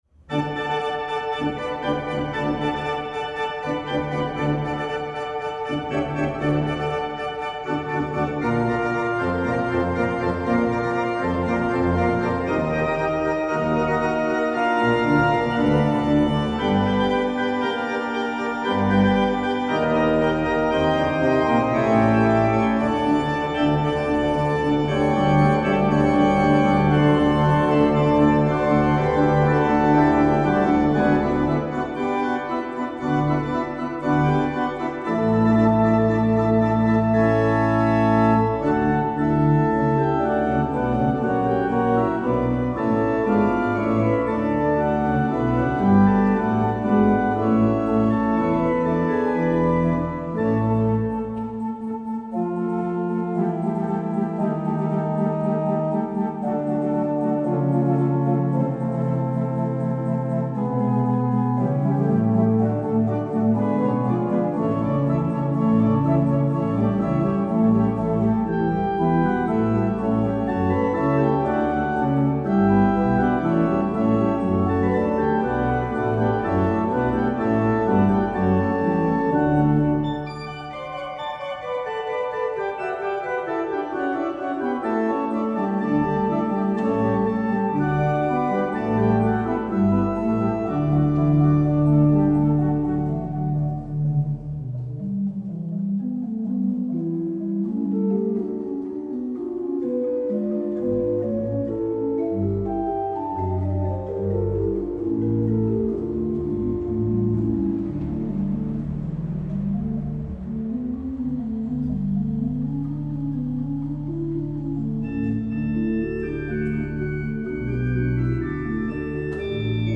Sommerlich inspirierte Grüße, gespielt auf der Orgel von St. Josef
Improvisation über das Lied „Geh aus mein Herz und suche Freud in dieser lieben Sommerzeit“